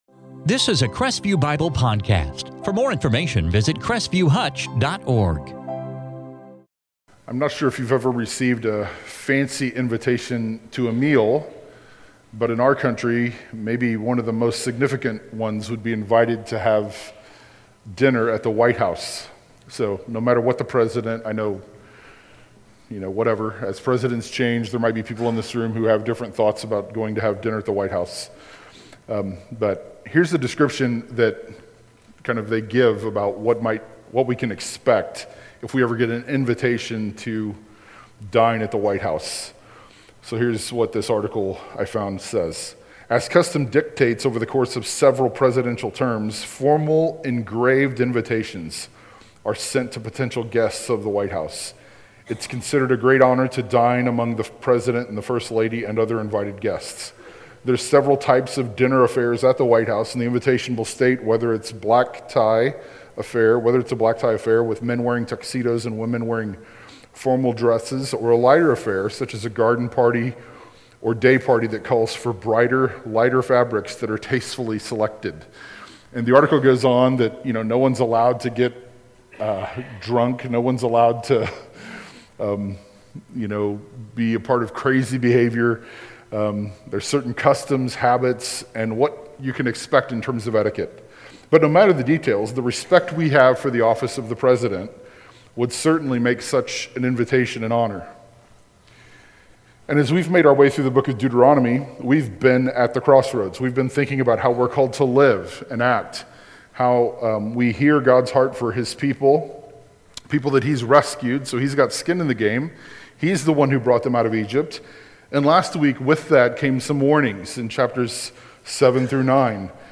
In this sermon from Deuteronomy 10-11,